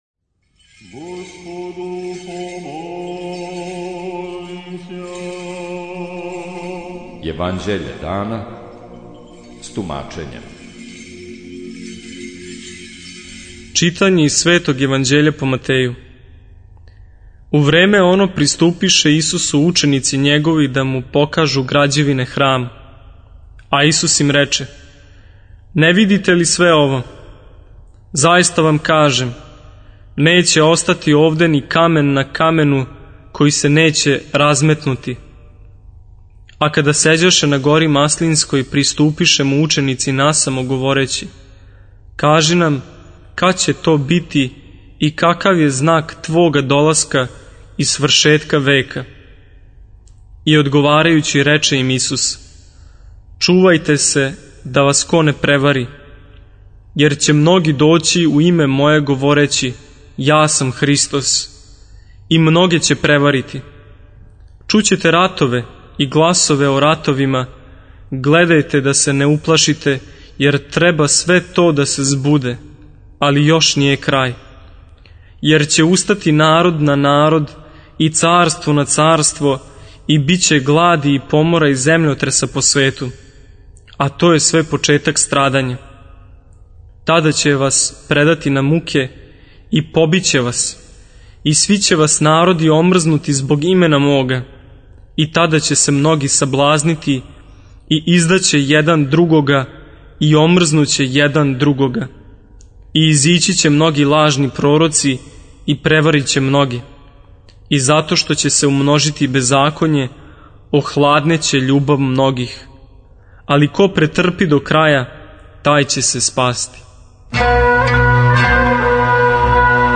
Читање Светог Јеванђеља по Луки за дан 20.12.2025. Зачало 67.